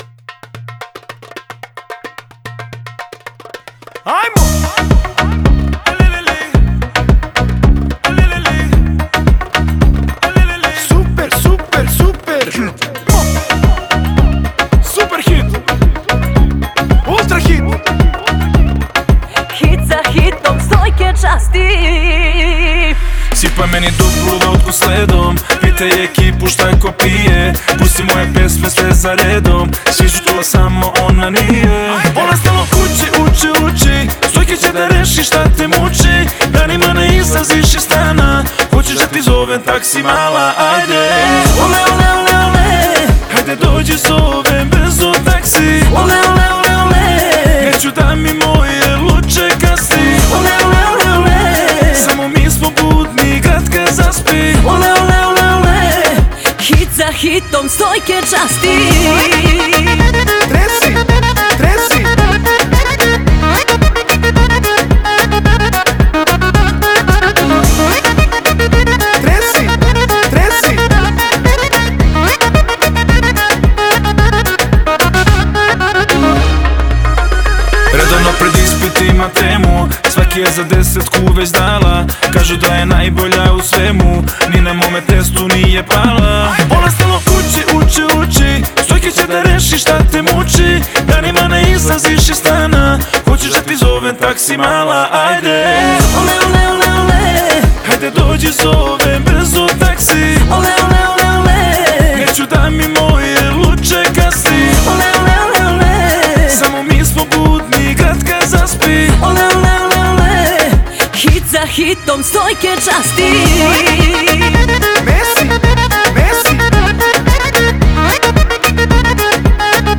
ритмичными битами, что побуждает слушателей танцевать.